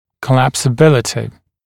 [kəˌlæpsə»bɪlətɪ][кэˌлэпсэ’билэти]стягиваемость, разрушаемость